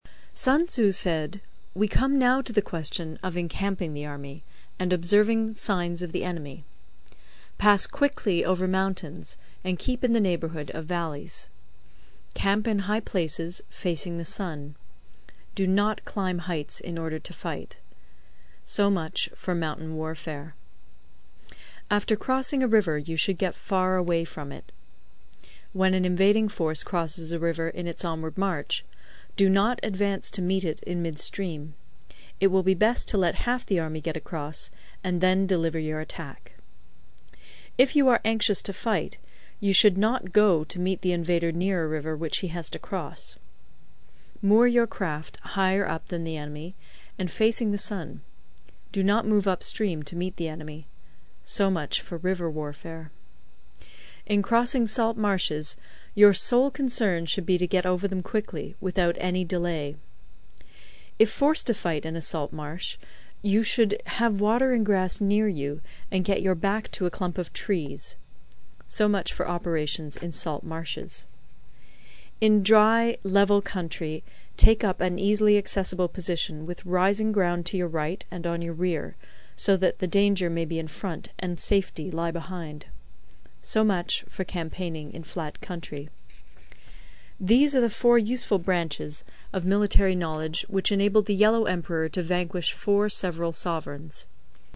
有声读物《孙子兵法》第50期:第九章 行军(1) 听力文件下载—在线英语听力室